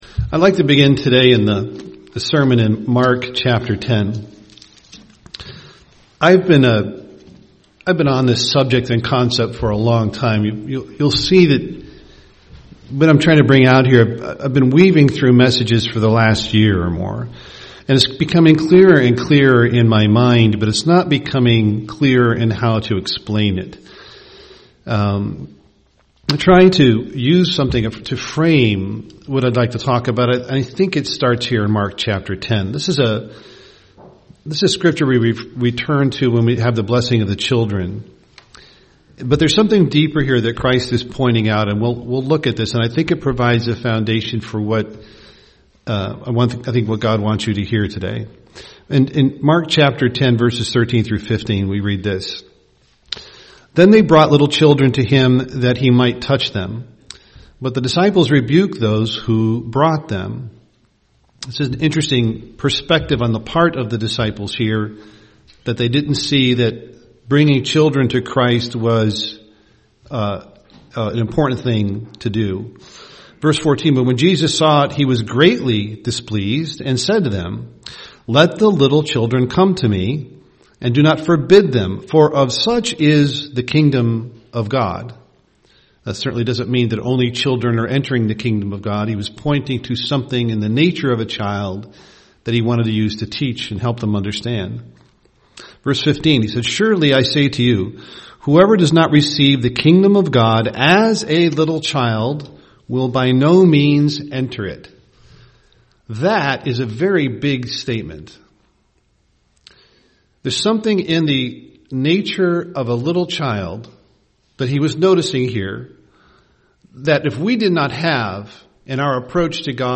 UCG Sermon humility Children child Studying the bible?